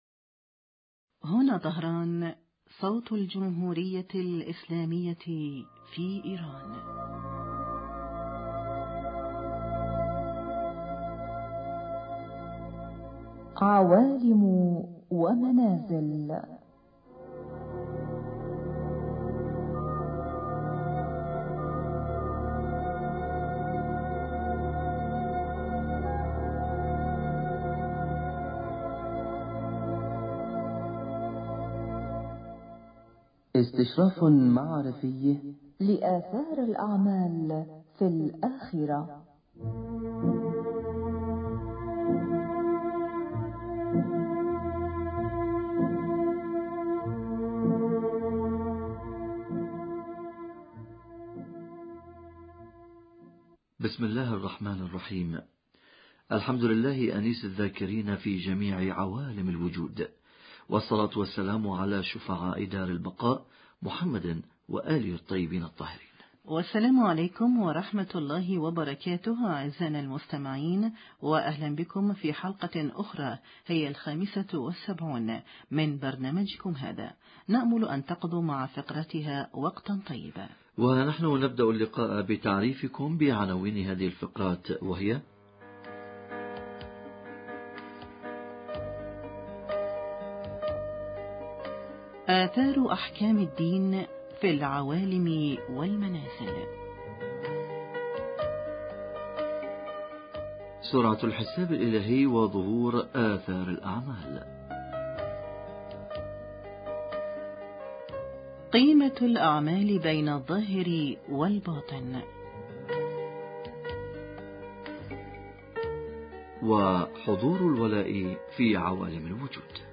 آثار الاحكام الشرعية على حياة الانسان في العوالم والمنازل المختلفة حوار